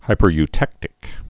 (hīpər-y-tĕktĭk)